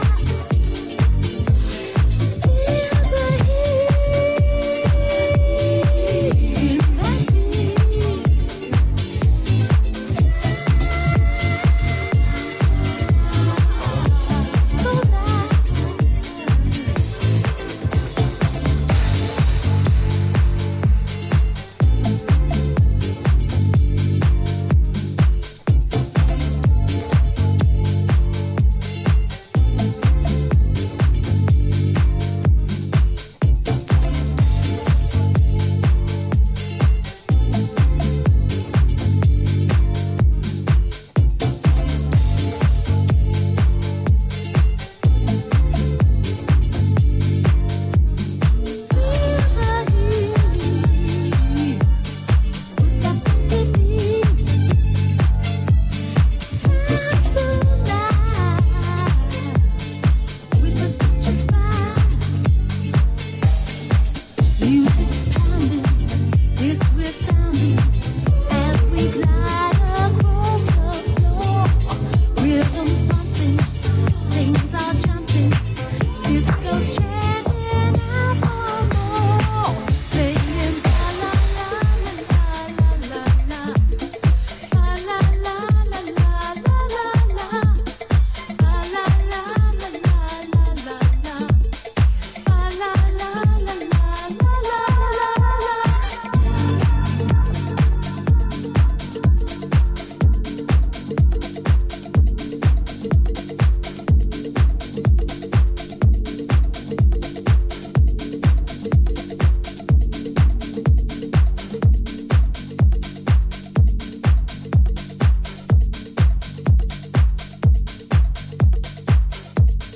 rolls effortlessly with a lush vocal that melts the loins